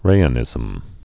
(rāə-nĭzəm)